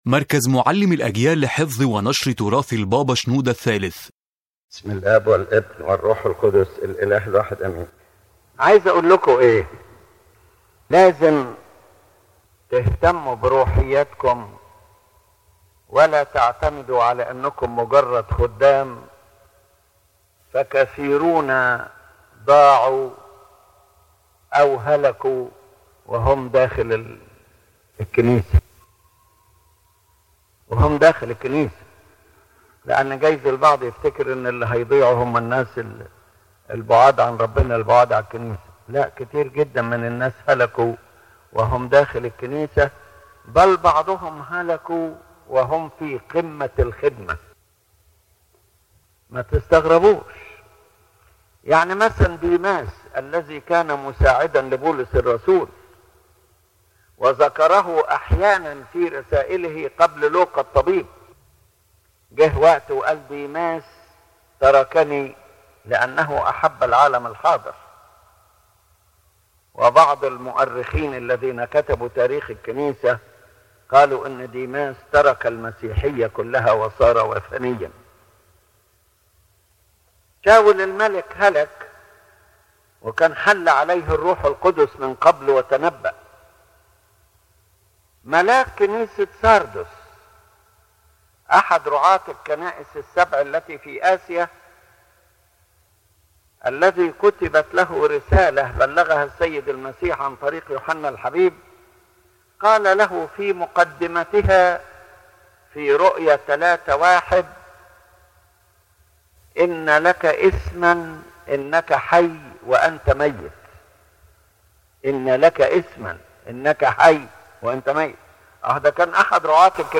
This lecture warns of the danger of spiritual loss even for those inside the church and involved in ministry. The speaker presents historical examples of men who served and then were lost or fell into heresy or pride, to show that outward belonging to the church does not guarantee salvation.